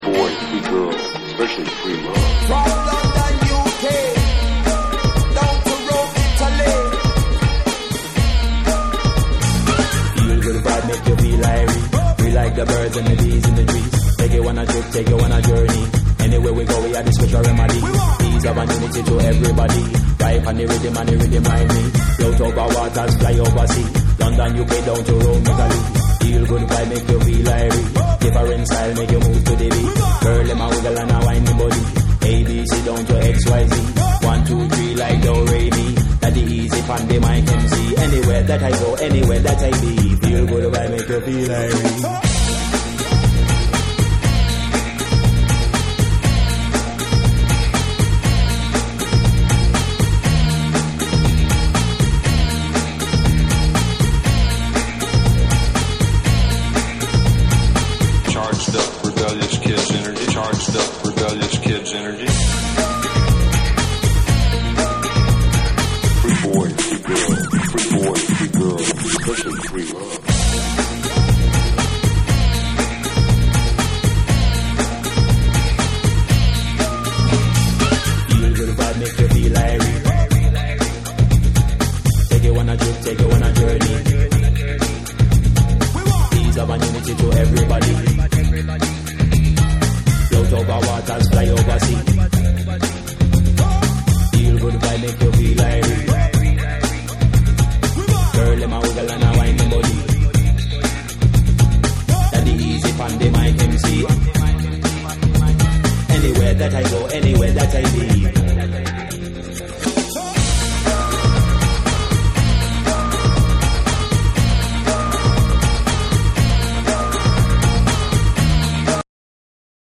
ジャケットのデザイン通りシタールのエスニックな旋律がアクセントとなったブレイクビーツ上を、ラガ調なフロウが煽る1。